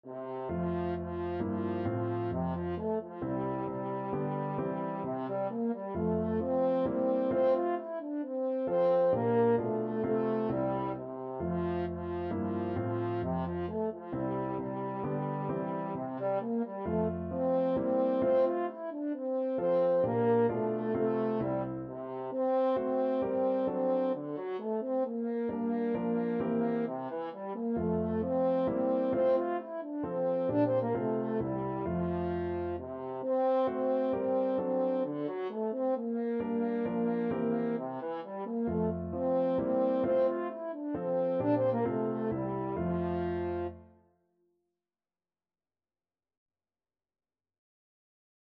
3/4 (View more 3/4 Music)
= 132 Allegro (View more music marked Allegro)
Classical (View more Classical French Horn Music)